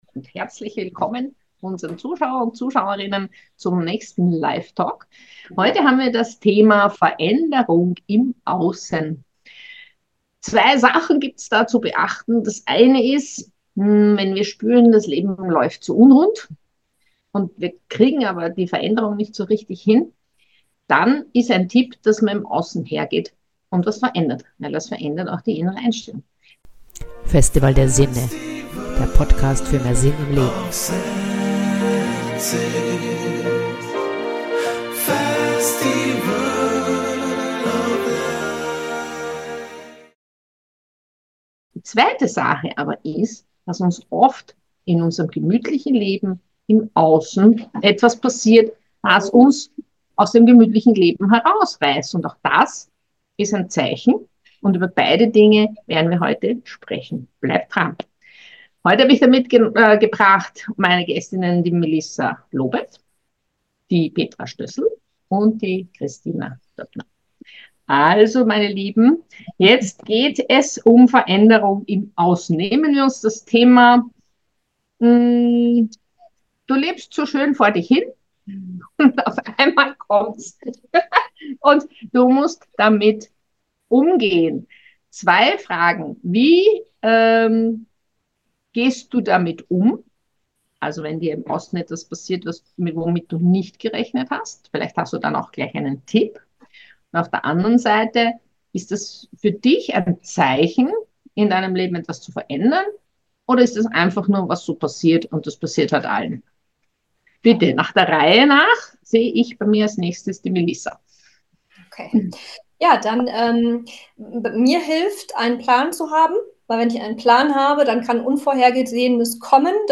veraenderung_im_aussen_lifetalk.mp3